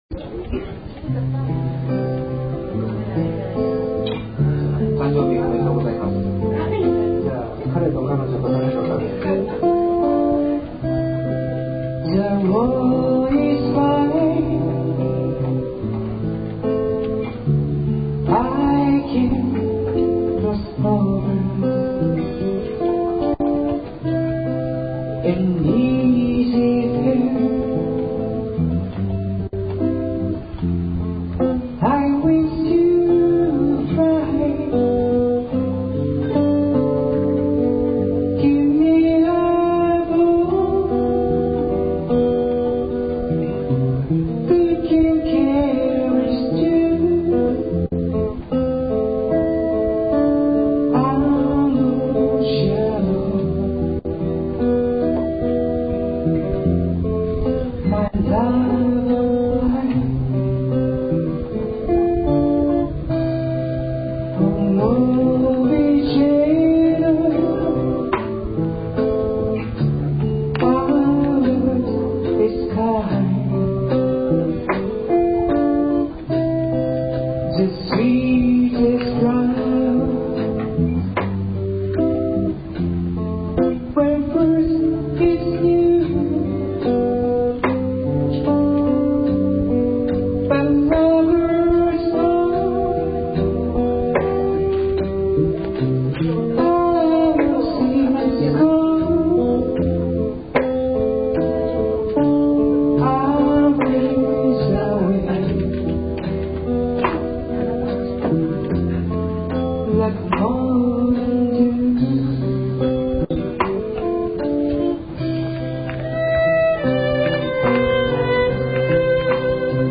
ライブ演奏（以前参加していたバンドのライブ演奏）